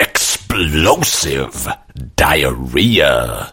Explosive Diahhrea
explosive-diahhrea-2.mp3